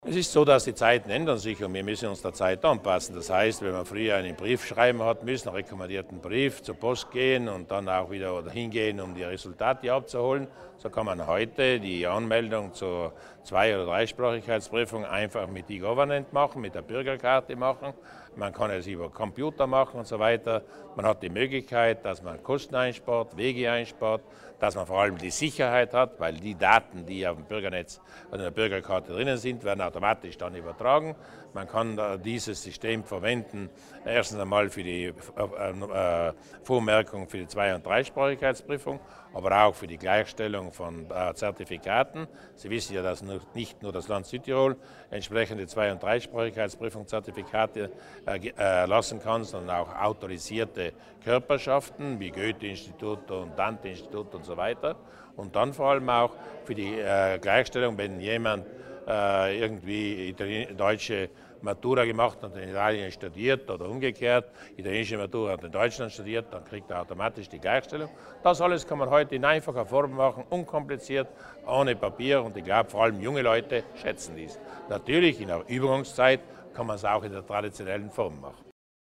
Landeshauptmann Durnwalder erklärt die neue Anmeldung zur Zweisprachigkeitsprüfung